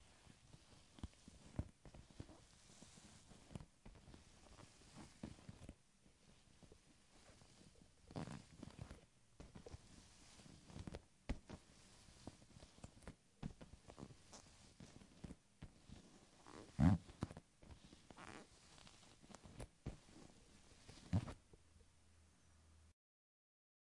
沙发挤压的沙沙声材料
描述：Tascam DR40线性PCM录音机 我蹲在沙发上发出声音 我使用Reaper编辑了音效。
Tag: 躺椅 沙沙作响 织物 OWI